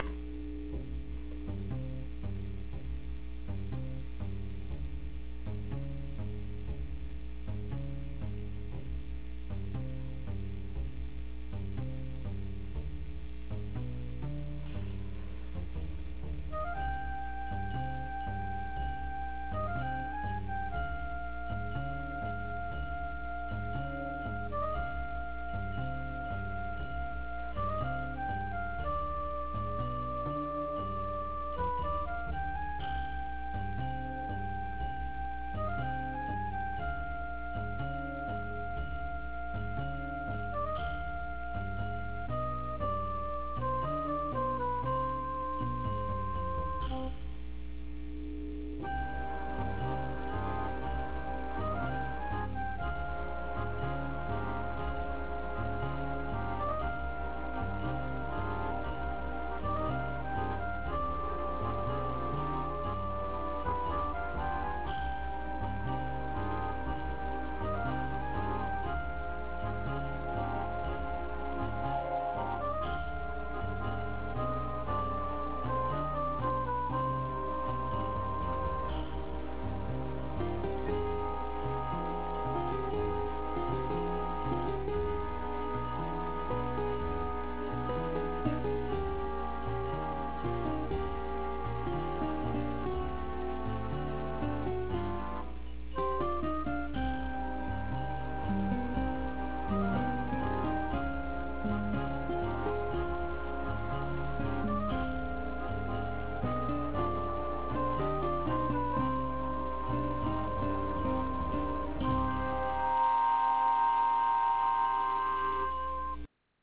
A Spanish flavor without good explanation!!